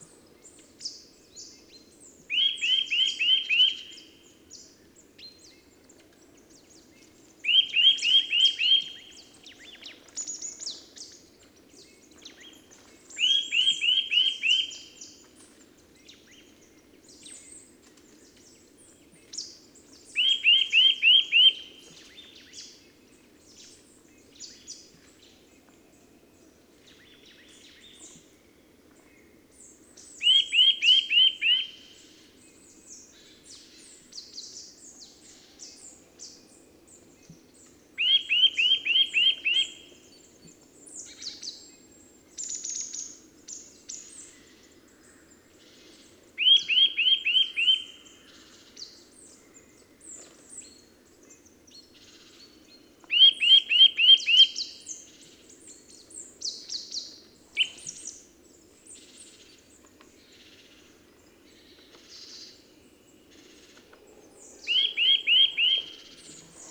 Kleiber Gesang
• Seine Stimme ist laut und pfeifend – ideal zur Reviermarkierung.
Er ruft laut und pfeifend, oft ein „wiü-wiü-wiü“ oder „zit-zit-zit“.
Kleiber-Gesang-Voegel-in-Europa.wav